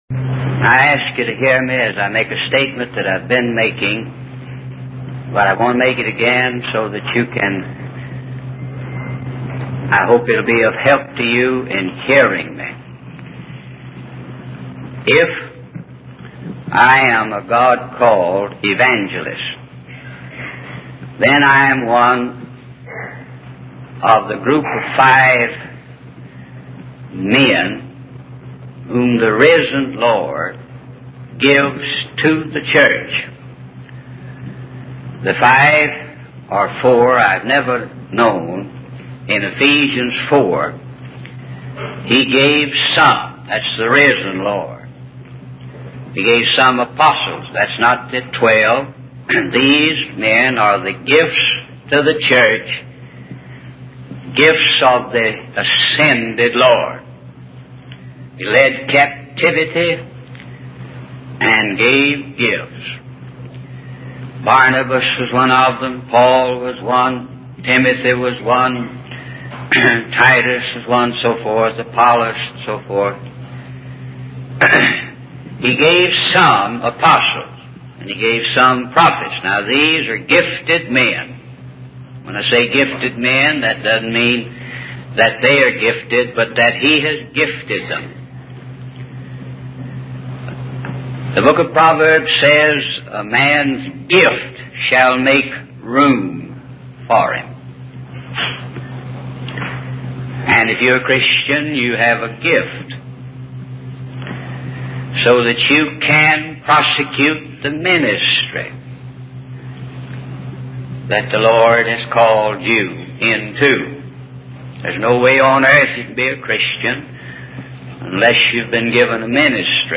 In this sermon, the preacher discusses the importance of repentance and baptism in the order of the church.